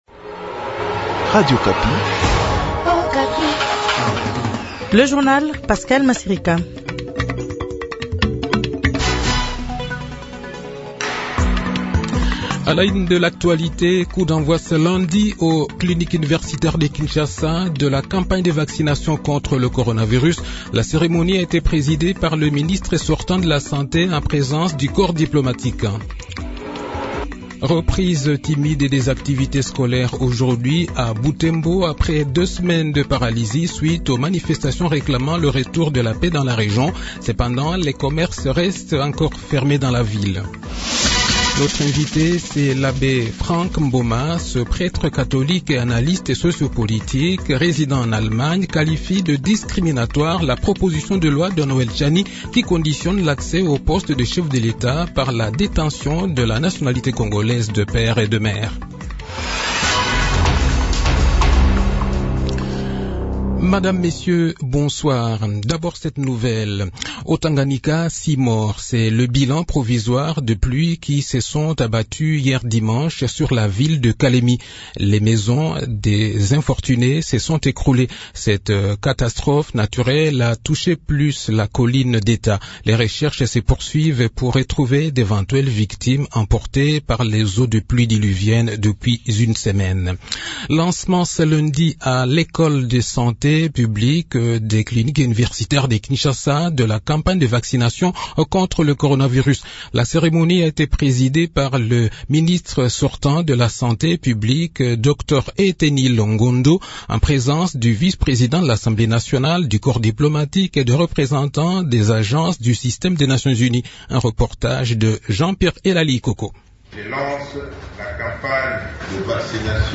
Le journal de 18 h, 19 Avril 202/